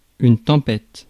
ÄäntäminenFrance (Paris):
• IPA: [yn ˈtɒ̃.pɛt]